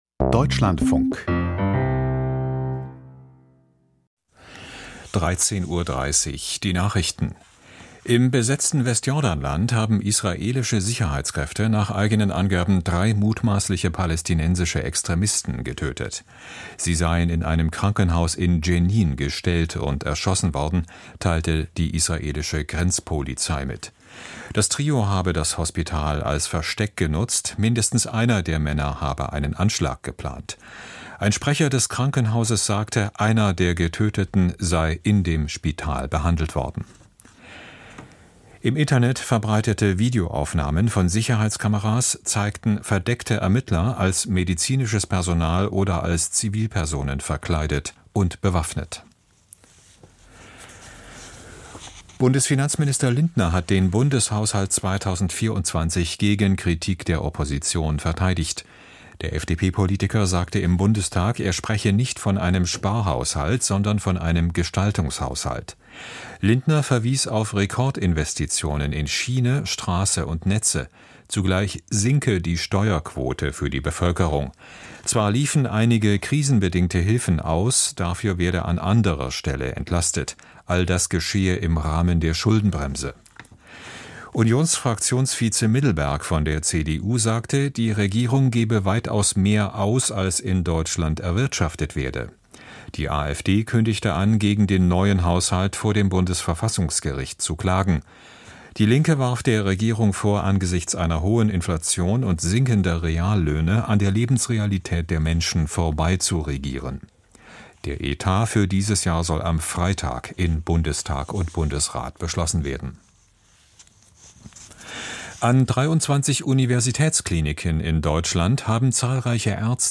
Wie gut war Taiwan auf das Erdbeben vorbereitet? Interview